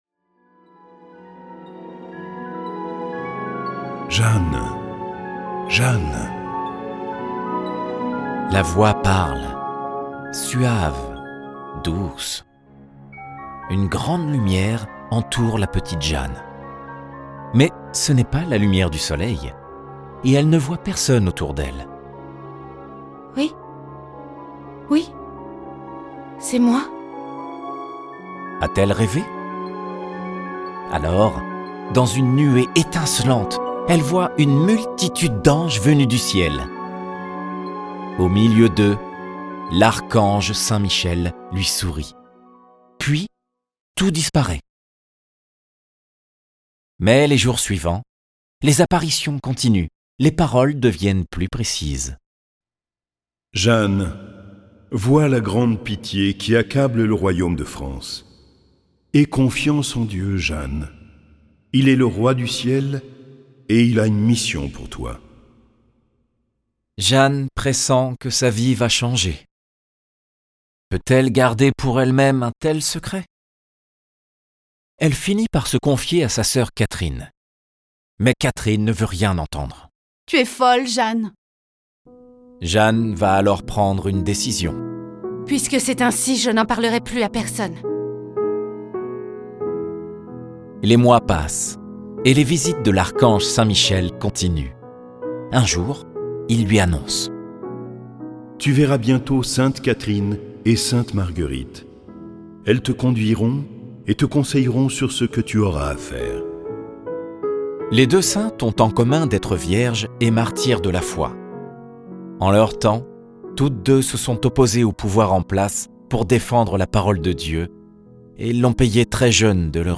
Cette version sonore de la vie de Jeanne est animée par plusieurs voix et accompagnée de musique classique.